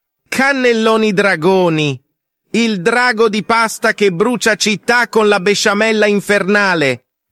Dragon Sound Effects MP3 Download Free - Quick Sounds